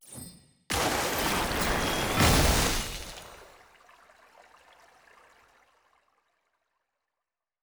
sfx-tft-skilltree-ceremony-water-crest-up.ogg